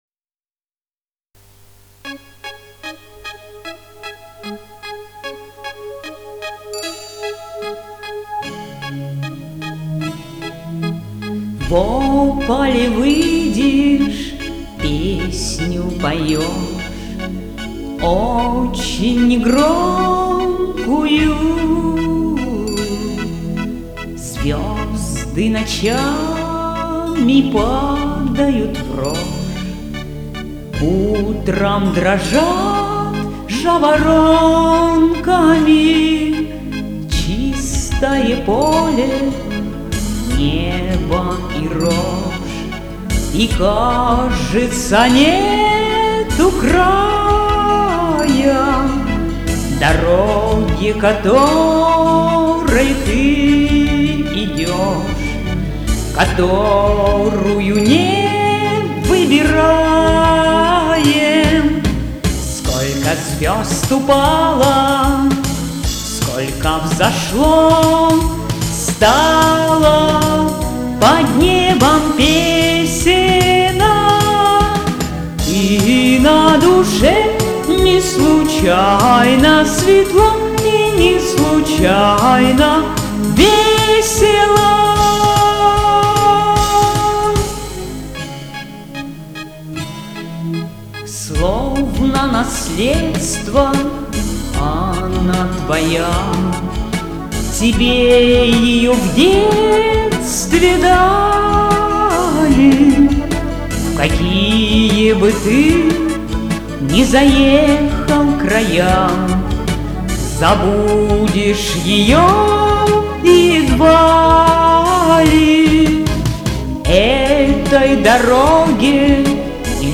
Еще редкий вариант в женском (не хор) исполнении: